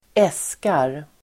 Uttal: [²'es:kar]